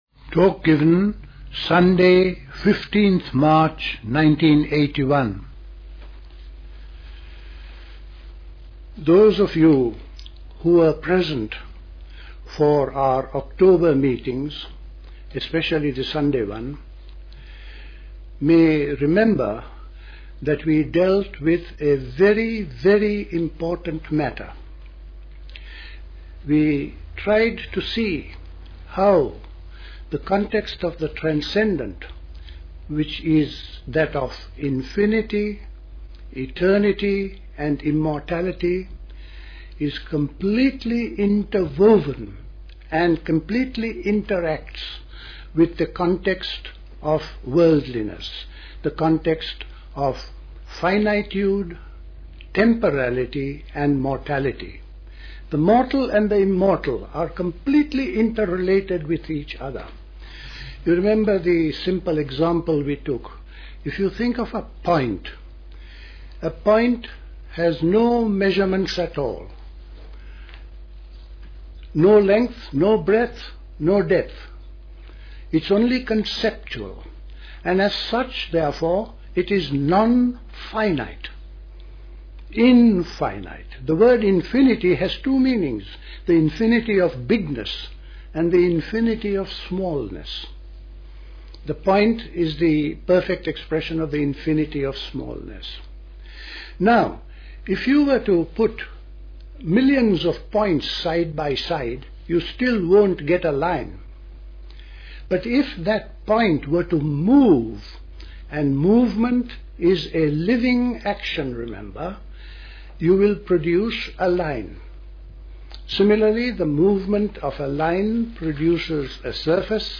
A talk
at Dilkusha, Forest Hill, London on 15th March 1981